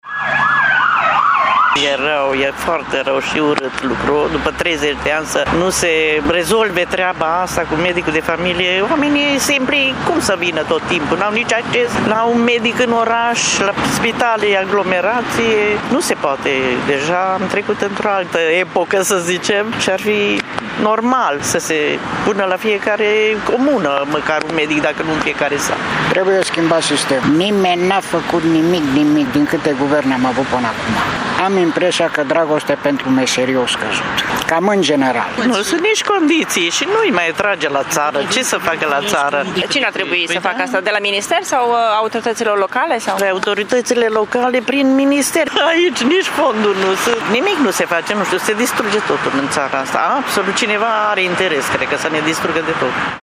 Oamenii cred că s-a ajuns în această situație din cauza lipsei de implicare a autorităților, dar și pentru că medicina nu mai este o vocație pentru generația tânără: